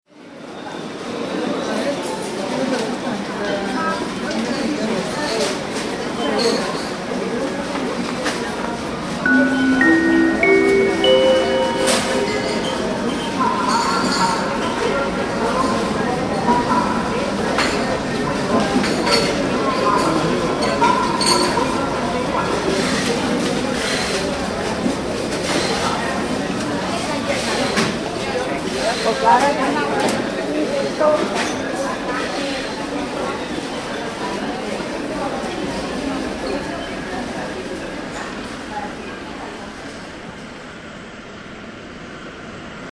L'espai que creareu és la cafeteria d'un aeroport a partir de tres fitxers:
Cadascun d'ells, de forma independent, són un espai: el soroll d'un avió quan s'enlaira, el soroll d'una cafeteria (aquest ambient el podem crear utilitzant els diferents sons que intervenen de forma independent) i el so d'una megafonia.
Per exemple, si sou a la cafeteria, el so ambient haurà de ser una mica més alt que el de l'avió, ja que representa que és més lluny, a fora.
• Heu creat un espai sonor que és la cafeteria d'un aeroport [
cafeaer.mp3